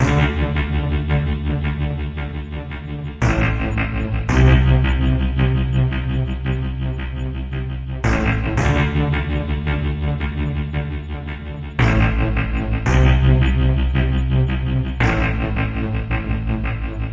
描述：跳舞riff循环112bpm
Tag: EDM 狂欢 舞蹈